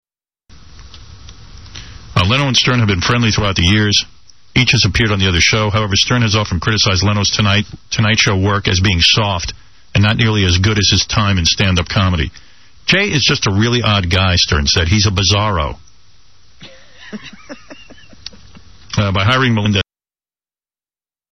Howard stern was ranting yesterday (Monday 2-23-04) about this situation. Today he read an excert from a newspaper that quoted his rants, one of which he calls Jay Leno a "Bizarro".